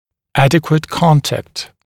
[‘ædɪkwət ‘kɔntækt][‘эдикуэт ‘контэкт]правильный контакт, адекватный контакт, достаточный контакт